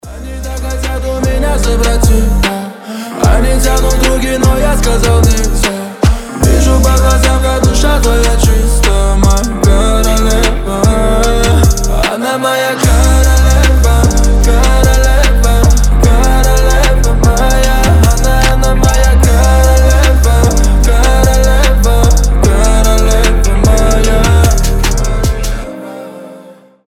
• Качество: 320, Stereo
лирика
басы
медленные